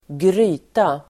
Uttal: [²gr'y:ta]